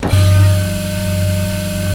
Electric Door Servo Loop